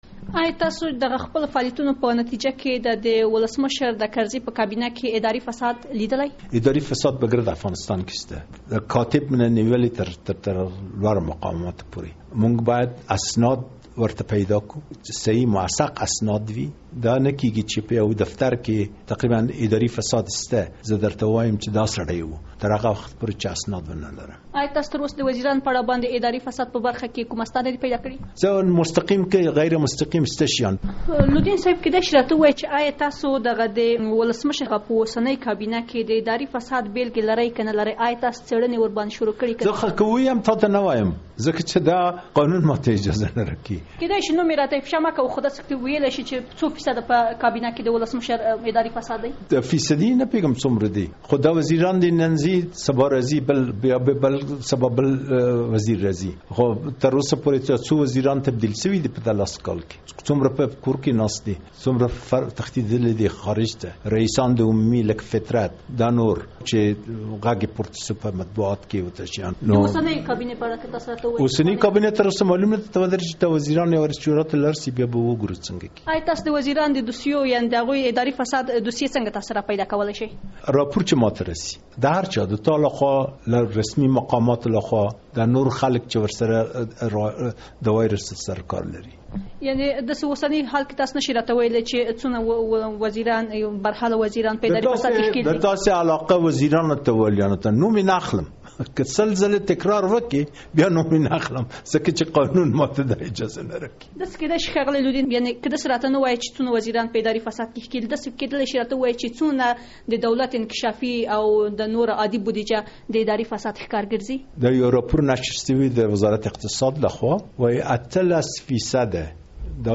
له عزیزالله لودین سره مرکه